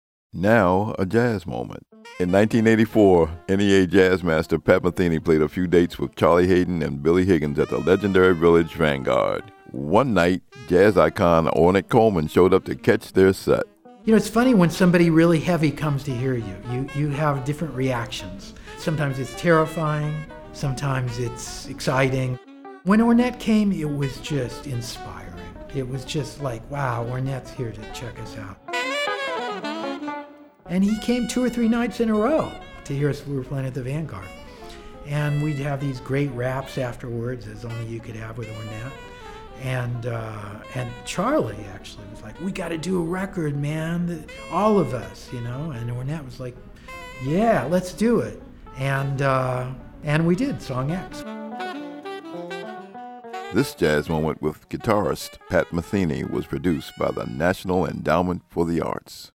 Song X under